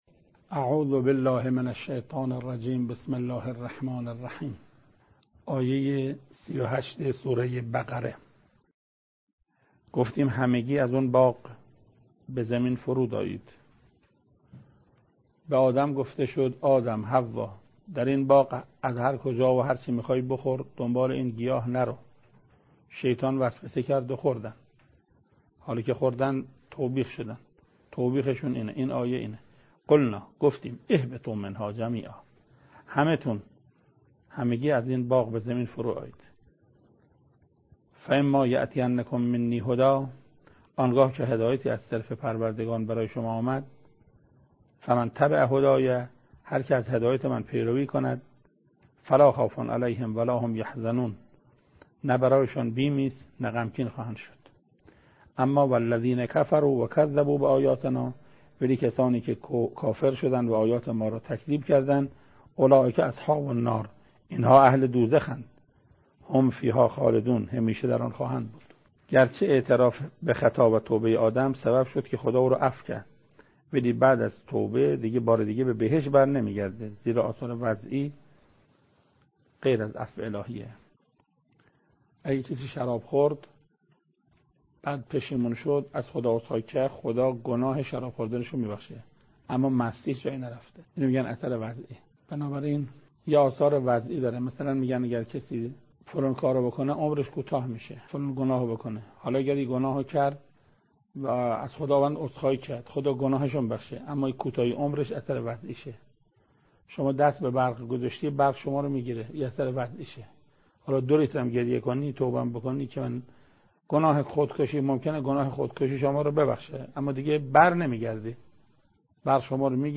تفسیر سوره (استاد قرائتی) بخش اول